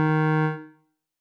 添加三个简单乐器采样包并加载（之后用于替换部分音效）